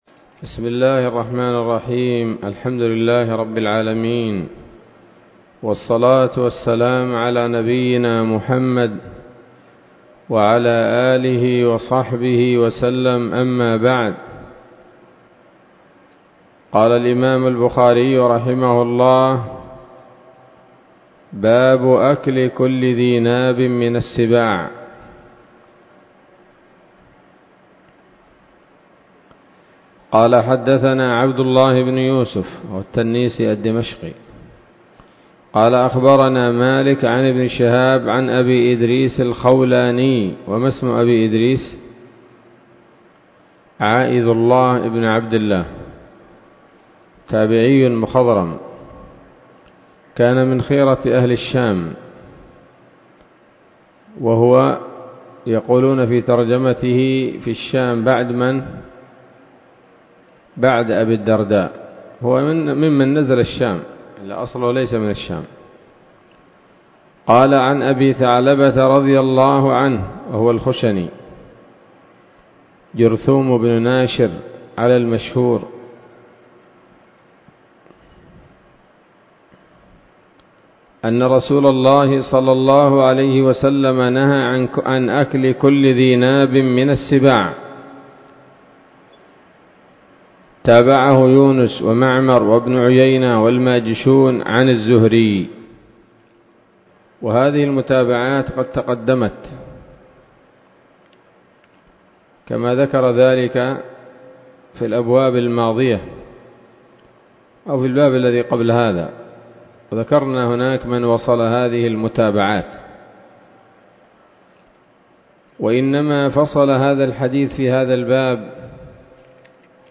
الدرس الخامس والعشرون من كتاب الذبائح والصيد من صحيح الإمام البخاري